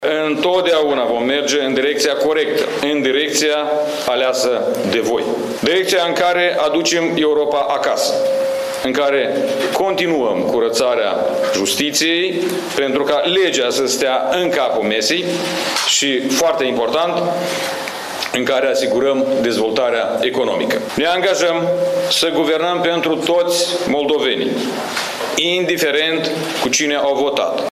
Declarații și de la șeful partidului câștigător – PAS – Igor Grosu. Acesta le-a mulțumit tuturor moldovenilor care au iești la vot și a subliniat că obiectivul fundamental este aderarea la Uniunea Europeană.